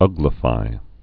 (ŭglə-fī)